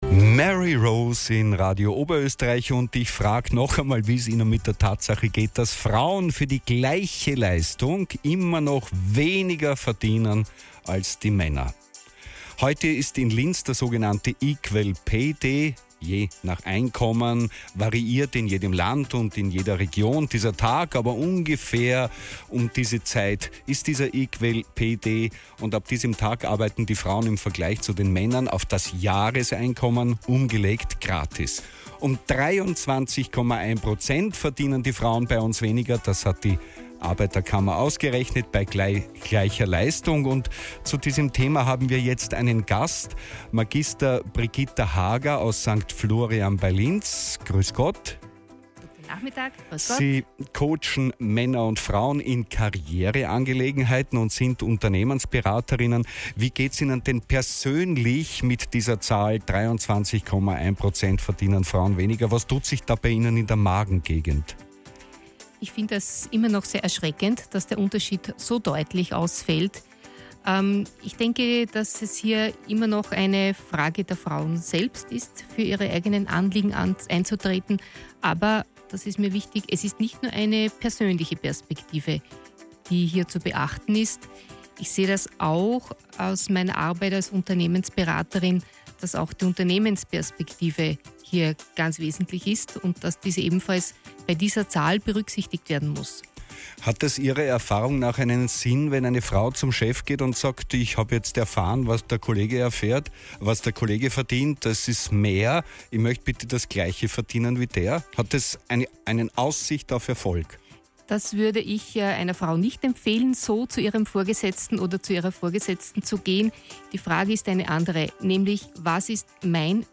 Interview (only in German available)